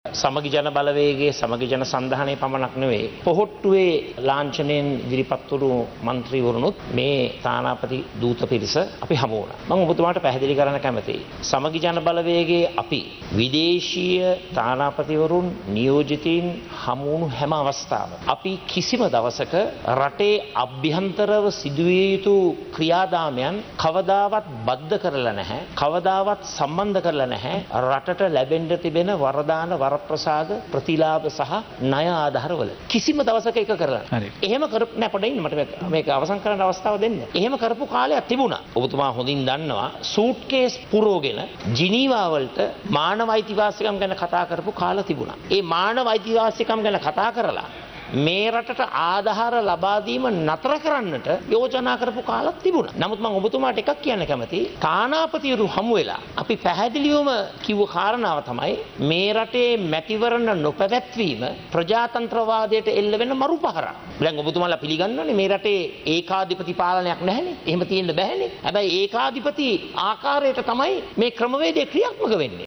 මේ අතර විපක්ෂ නායක සජිත් ප්‍රේමදාස මහතා අද පාර්ලිමේන්තුවේදී කියා සිටියේ මුදල් ලබා නොදීම හේතුවෙන් පළාත් පාලන මැතිවරණය යළිත් කල්යනු ඇති බවටයි.
මේ ඒ පිළිබදව වැඩිදුරටත් අදහස් දැක්වූ විපක්ෂ නායක සජිත් ප්‍රේමදාස මහතා.